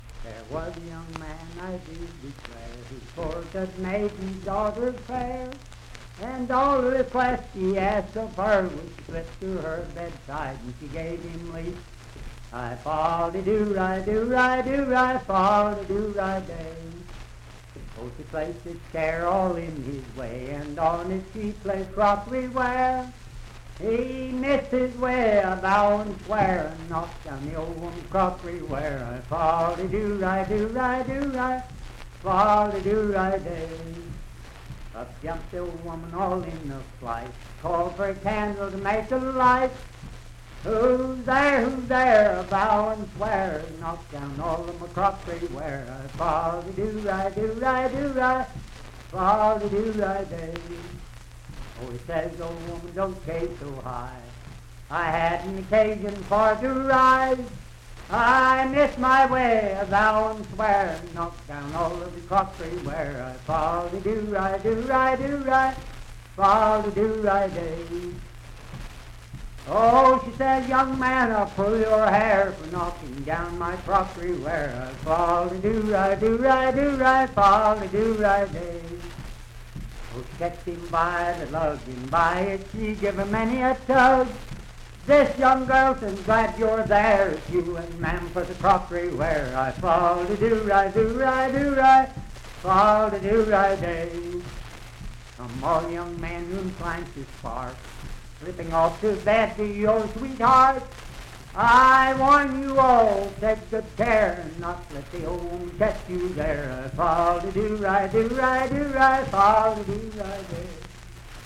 Unaccompanied vocal music
Verse-refrain 6(6w/R).
Voice (sung)
Parkersburg (W. Va.), Wood County (W. Va.)